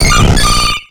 Cri de Leuphorie dans Pokémon X et Y.